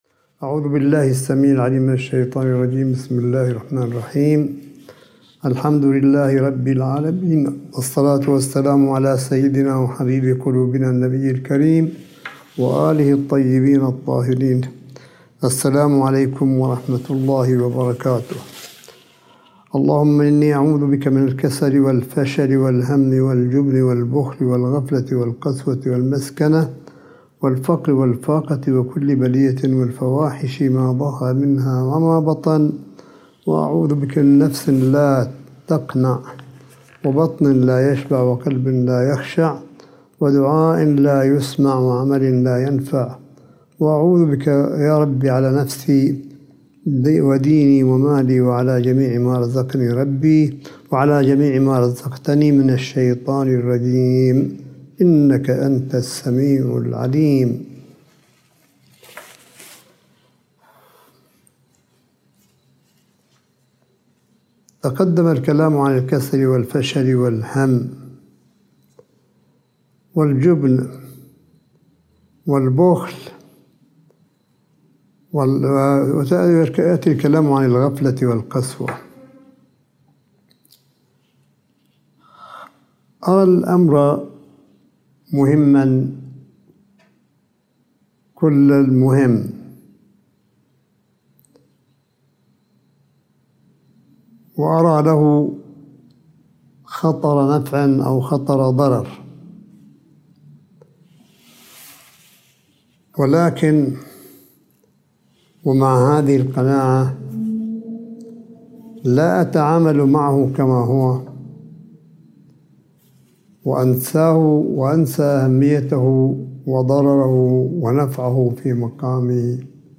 ملف صوتي للحديث الرمضاني (27) لسماحة آية الله الشيخ عيسى أحمد قاسم حفظه الله – 28 شهر رمضان 1442 هـ / 10 مايو 2021م